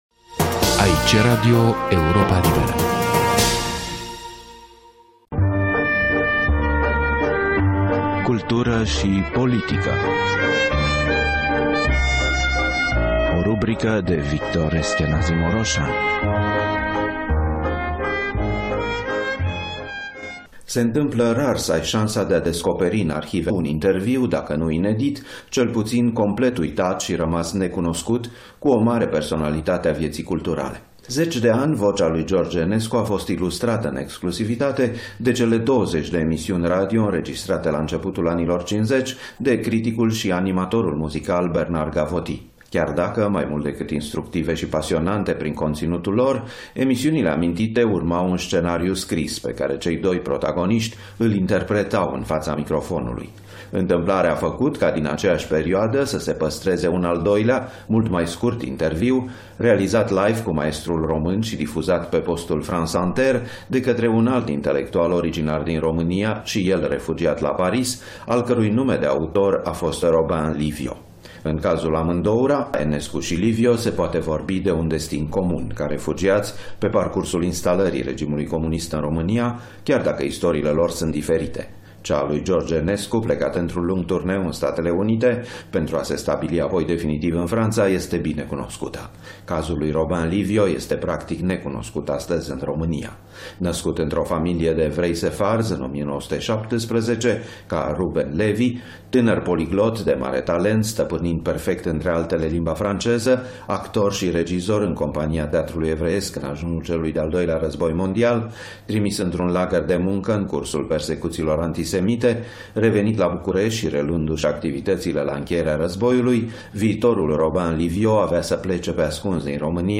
Pe marginea unui interviu uitat al compozitorul român păstrat în arhivele Institutului Național al Audiovizualului din Franța.